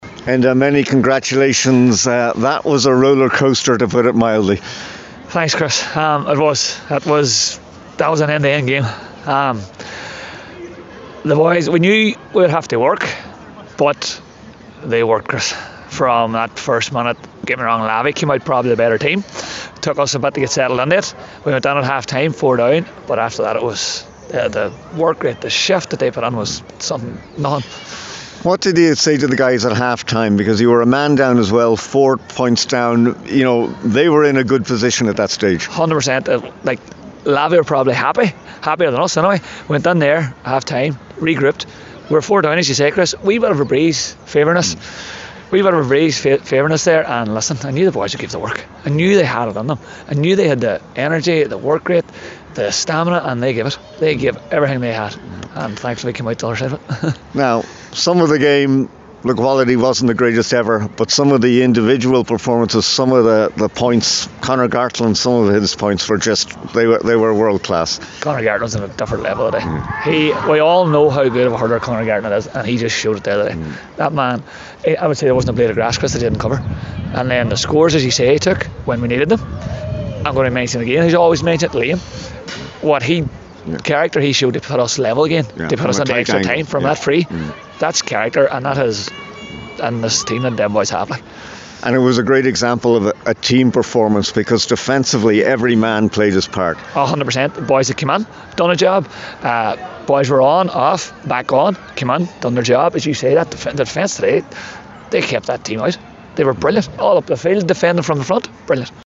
at full time in Derry…